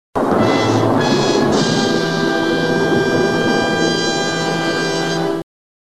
PLAY dramatic boom
Play, download and share Dramatic original sound button!!!!